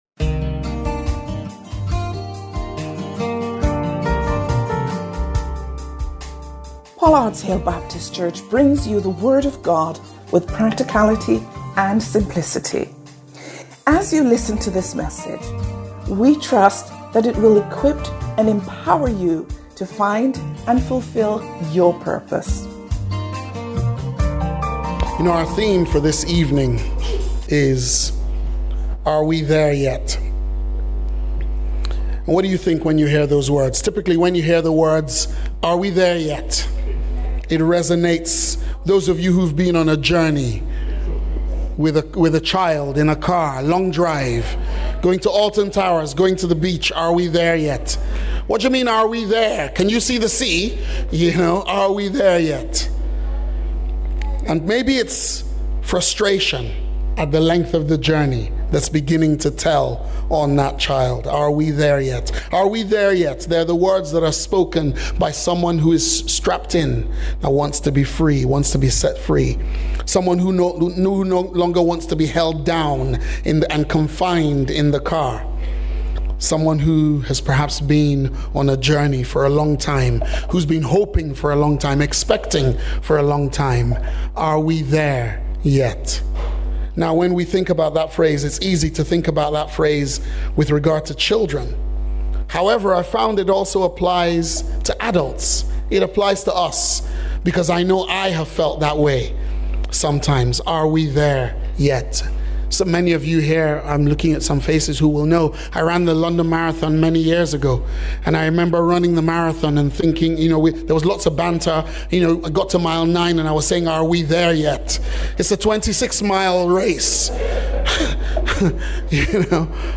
Sermons – Pollards Hill Baptist Church